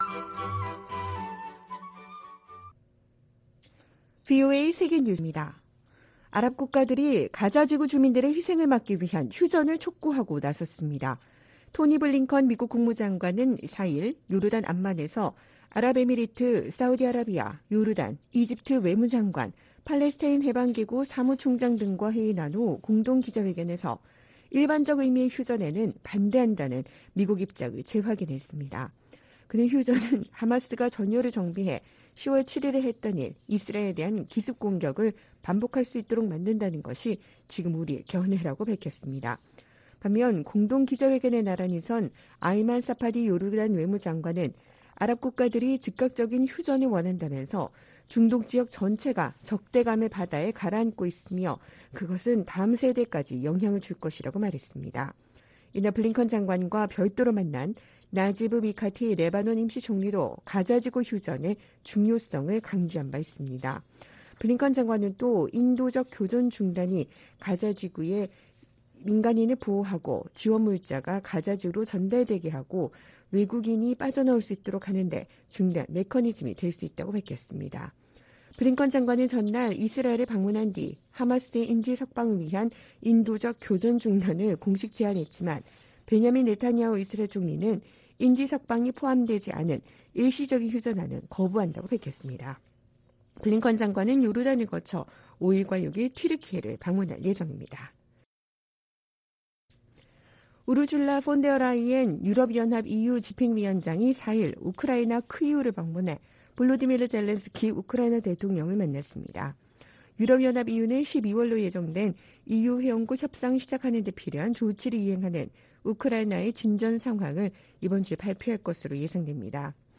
VOA 한국어 방송의 일요일 오후 프로그램 2부입니다. 한반도 시간 오후 9:00 부터 10:00 까지 방송됩니다.